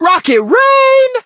brock_ulti_vo_04.ogg